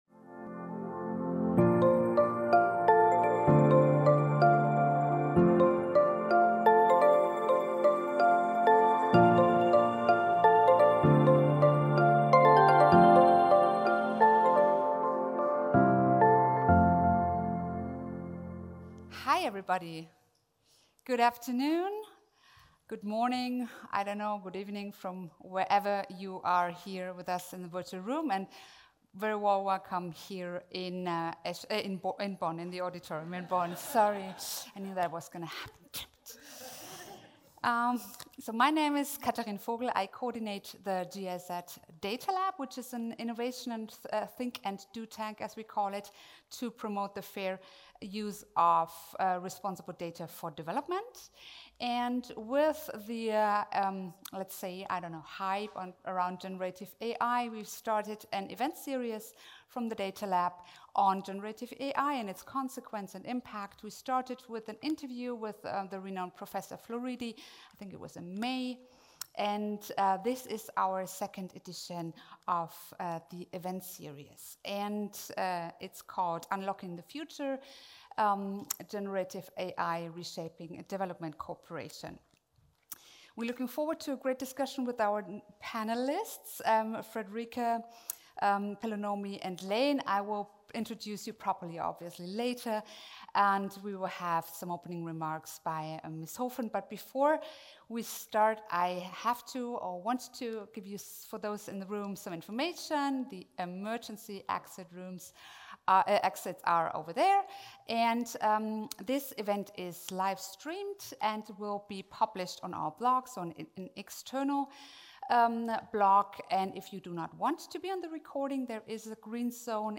NB: We have included an audio-only version of the event for those who may have issues with video playback or just like to enjoy podcasts on the go – please find the audio file under the videos.